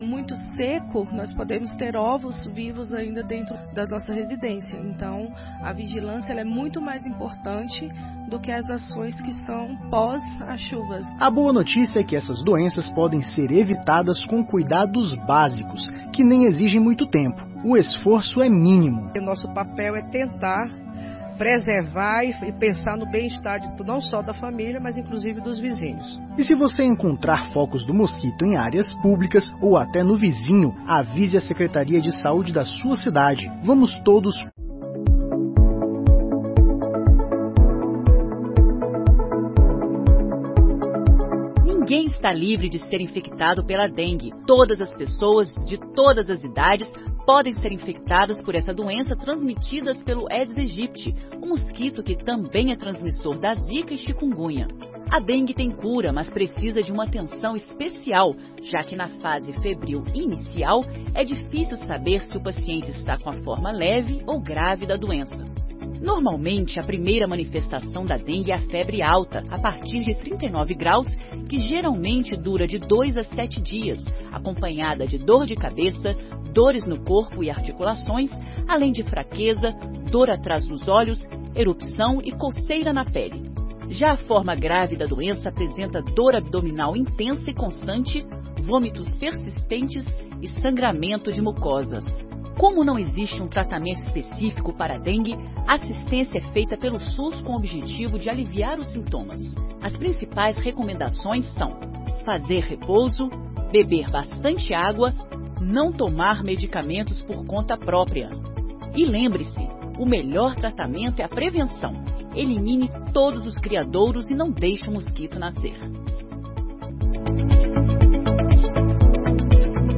Outorga de Títulos de Cidadão Benemérito e Garcense - 04/05/2022
Sessões Solenes de 2022